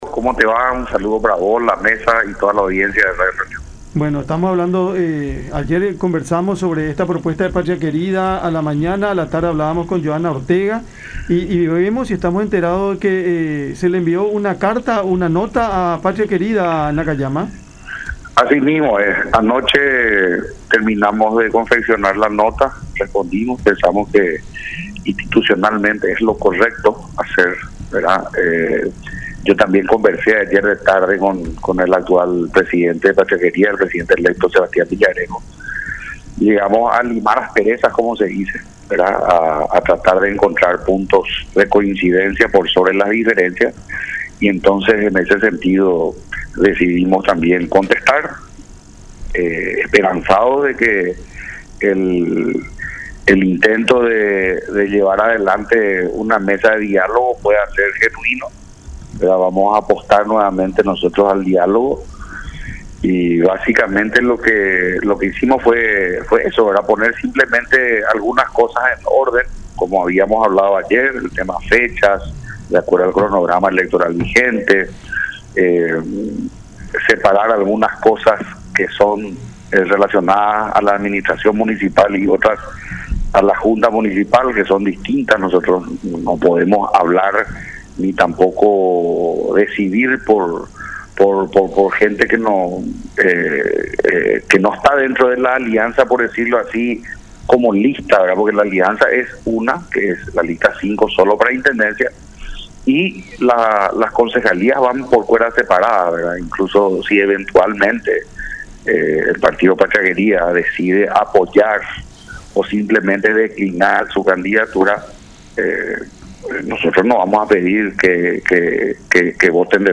Esa es la forma correcta”, dijo Nakayama en charla con Cada Mañana por La Unión.